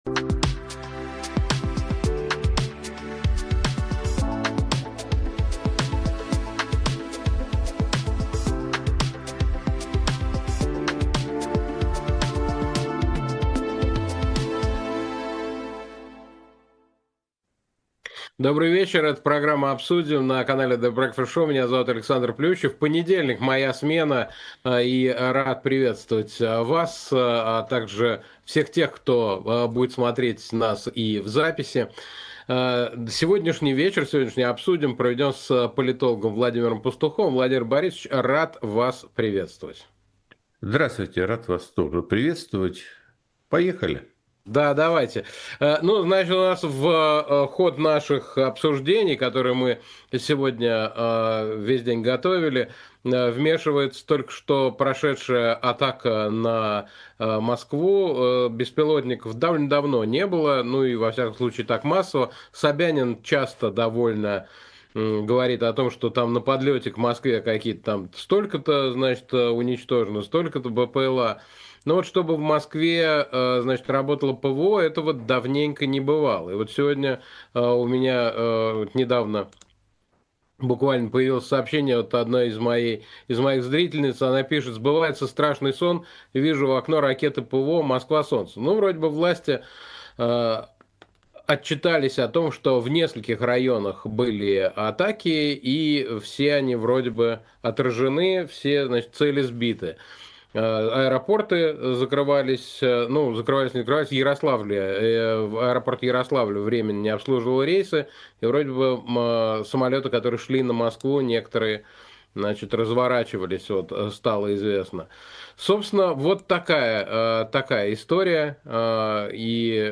Эфир ведёт Александр Плющев
Мученик Кирк 22.09.25 Скачать Подписаться на The Breakfast Show Поддержать канал The Breakfast Show Поддержать «Эхо» В программе «Обсудим» мы в прямом эфире говорим о самых важных событиях с нашими гостями. Гость сегодняшнего выпуска — политолог Владимир Пастухов . Обсудим с ним, начнет ли Европа сбивать российские военные самолеты, кто признает Палестину, как Чарли Кирк стал христианским мучеником, и многое другое.